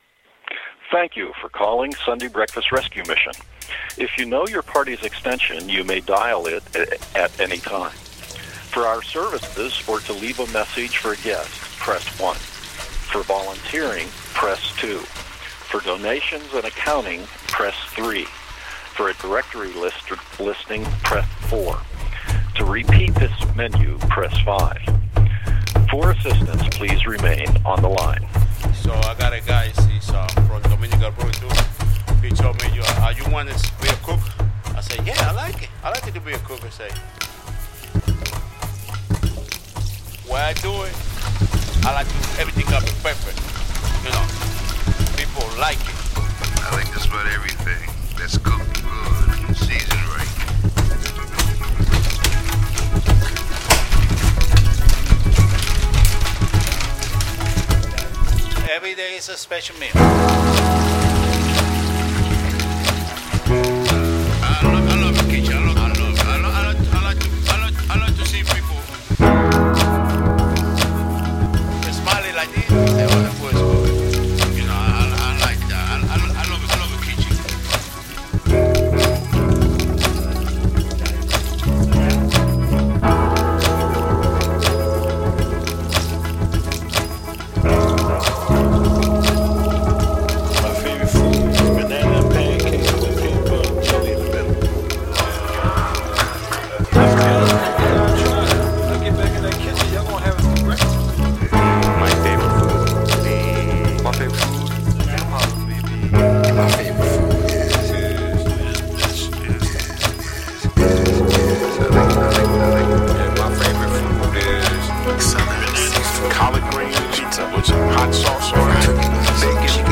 “Sunday Breakfast” is a collage of field recordings of kitchen sounds,hopeful lo-fi musical themes, and snippets of men from the Sunday Breakfast Rescue Mission talking about their favorite foods